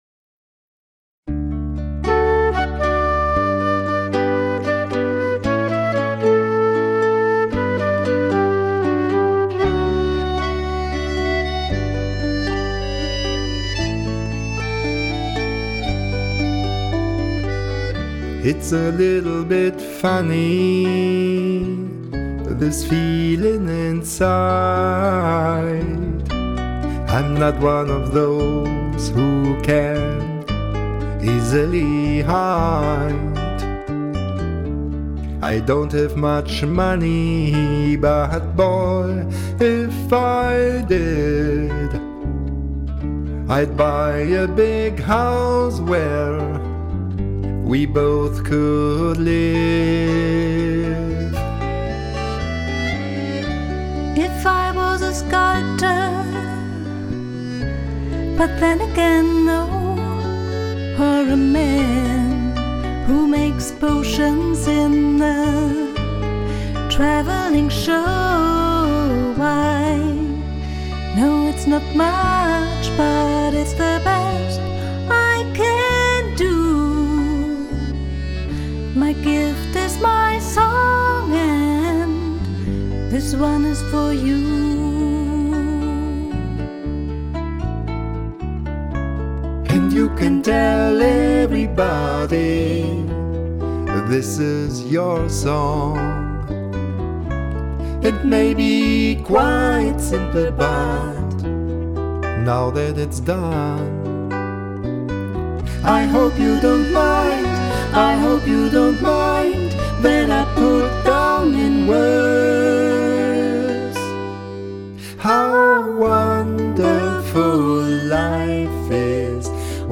Cover
Jedoch mit zwei Konzertgitarren und einem Akkordeon.
Vom Gefühl her finde ich, ist es sehr "trocken" gemischt...